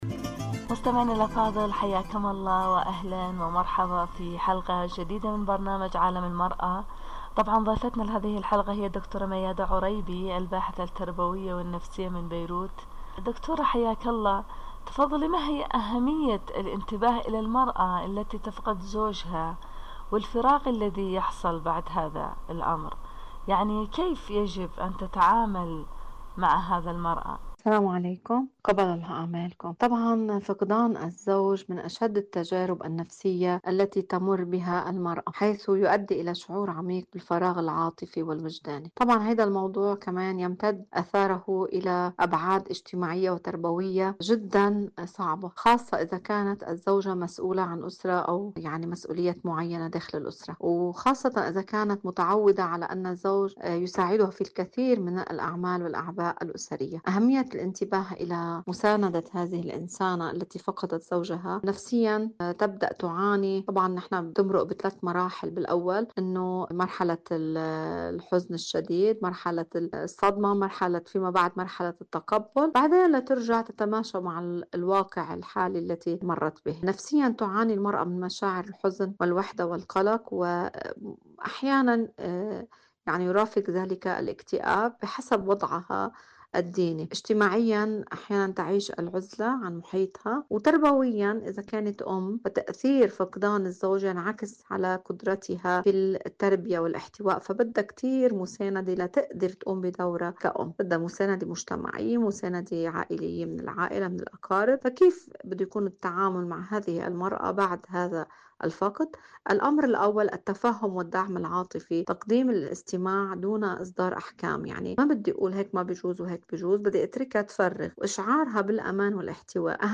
إذاعة طهران- عالم المرأة: مقابلة إذاعية